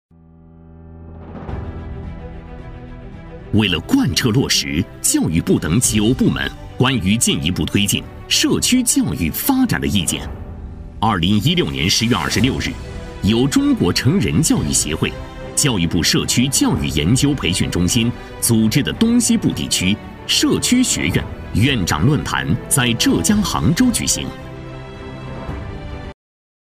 配音风格： 磁性，年轻
【专题】教育